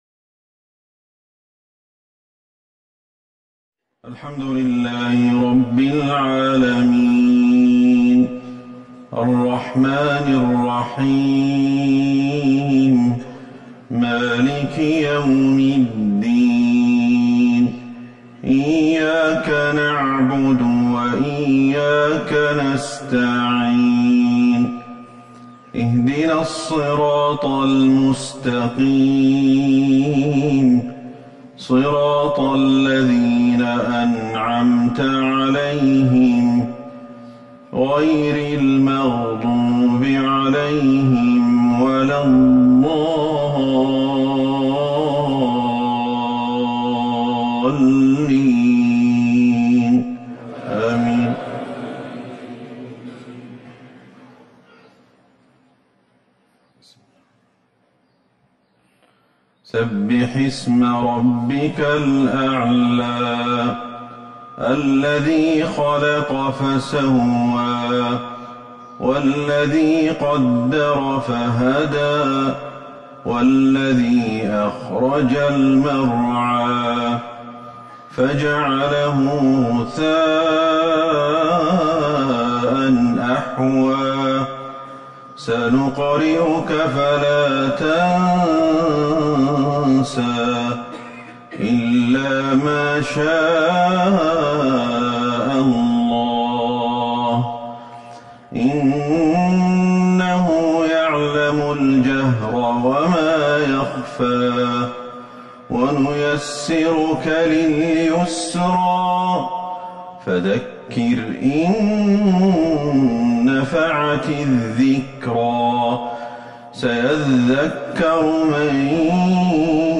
صلاة المغرب ١٥ جمادى الآخرة ١٤٤١هـ سورتي الأعلى والتين | Maghrib prayer 9-2-2020 Surti Al-Alawi and Altin > 1441 🕌 > الفروض - تلاوات الحرمين